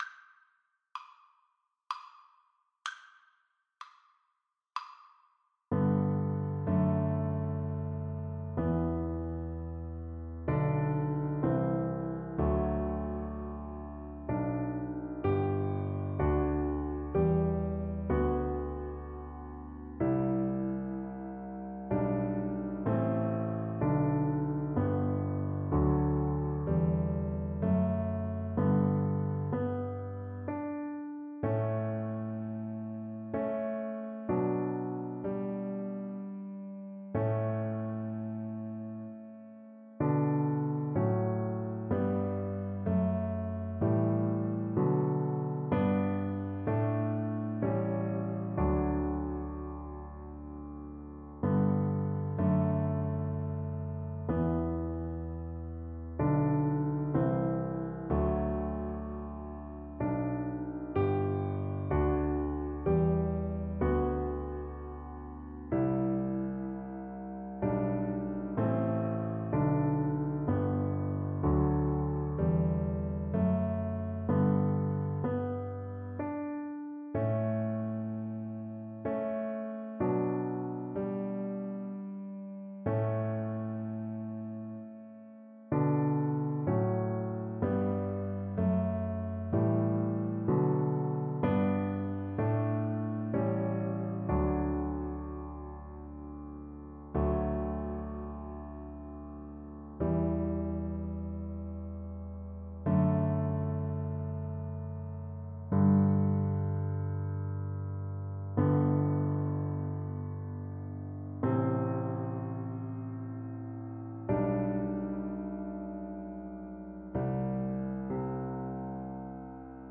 Cello version
3/4 (View more 3/4 Music)
Classical (View more Classical Cello Music)